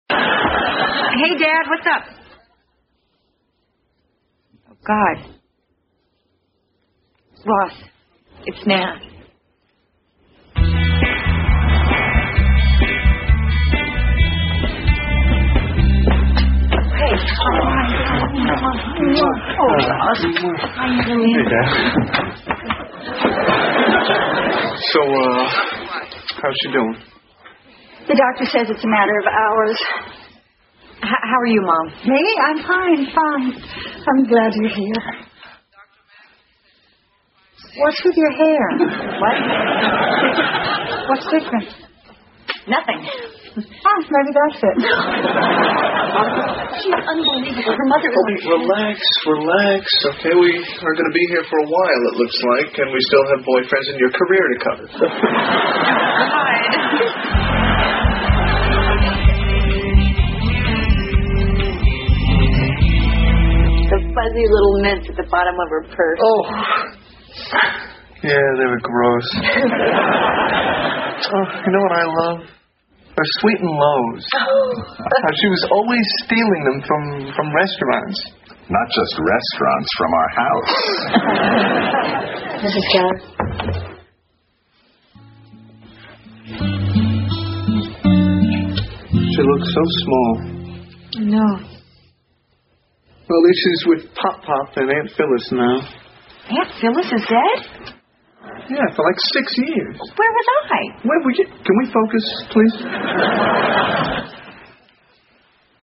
在线英语听力室老友记精校版第1季 第89期:祖母死了两回(3)的听力文件下载, 《老友记精校版》是美国乃至全世界最受欢迎的情景喜剧，一共拍摄了10季，以其幽默的对白和与现实生活的贴近吸引了无数的观众，精校版栏目搭配高音质音频与同步双语字幕，是练习提升英语听力水平，积累英语知识的好帮手。